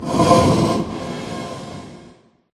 mob / blaze / breathe3.ogg
breathe3.ogg